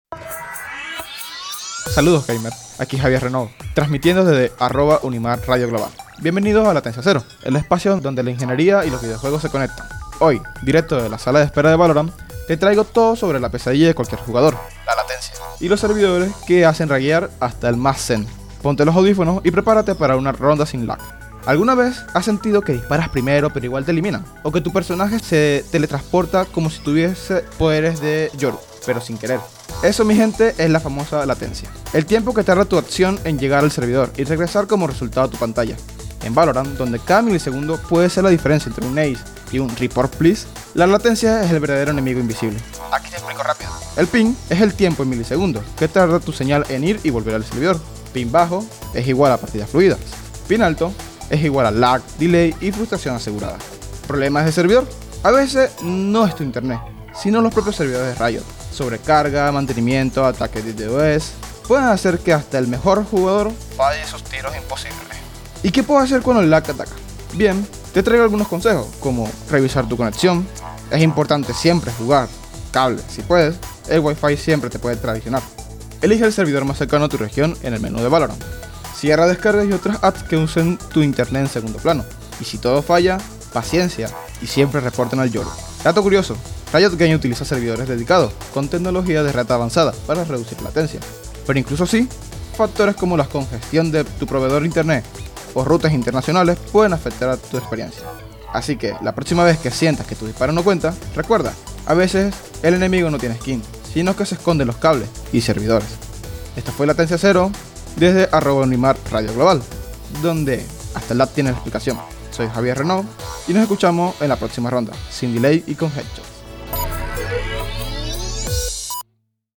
Cada episodio explora sistemas electrónicos y sus invenciones, desde redes digitales hasta algoritmos que mueven el mundo. Con ejemplos cotidianos, humor y entrevistas a expertos, este espacio conecta la teoría con la vida diaria.